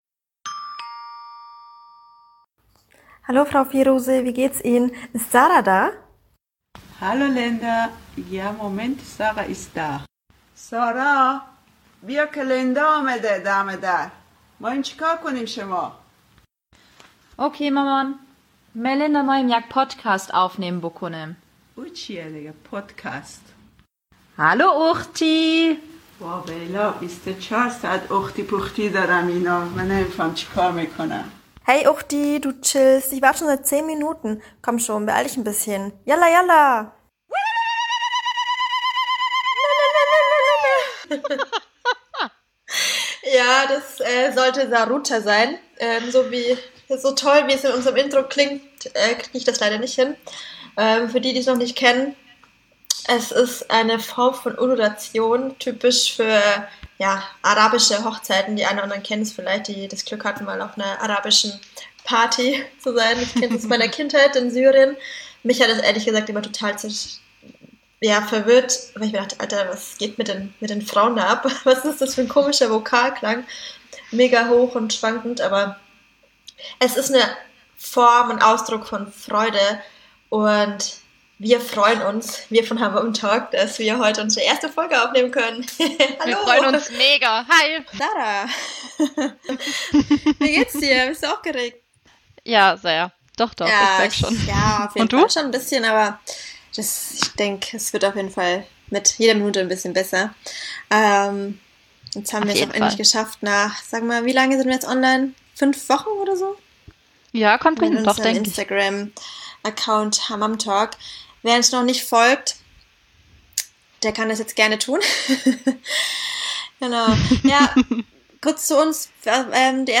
Heute wird der Begriff aber auch im Veganismus, Feminismus, Klimaaktivismus usw. verwendet. ⠀ ⠀ PS: Dieses Mal mit einem Echo-Spezialeffekt.